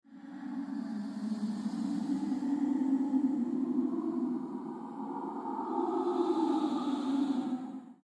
Paired with haunting ambient tones, this short video evokes calm, mystery, and quiet awe.